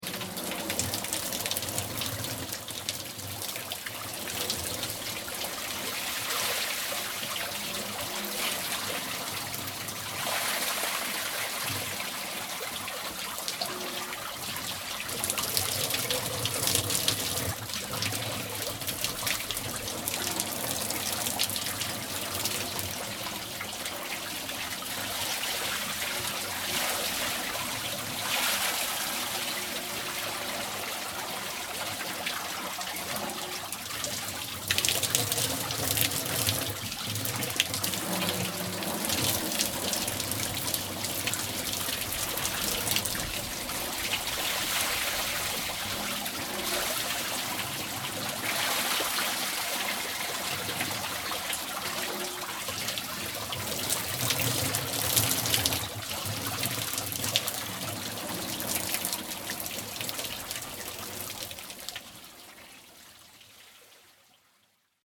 高原の水車
これが水車の音。